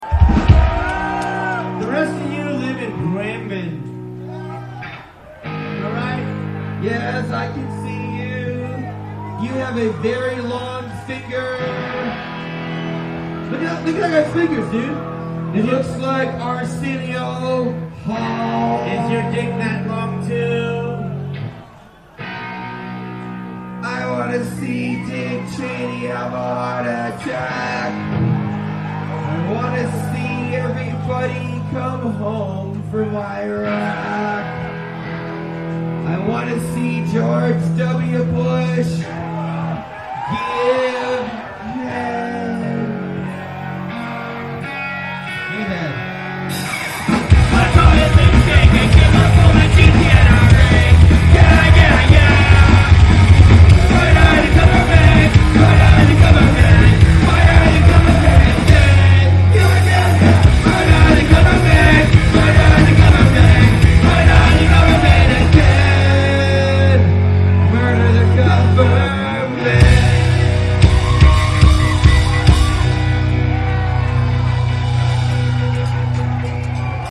Recorder: Sharp IM-DR420H (LP4-Mode)
Microphone: Sony ECM-T6 (Mono)